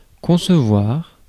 Ääntäminen
IPA: /kɔ̃səvwaʀ/ IPA: /kɔ̃.sə.vwaʁ/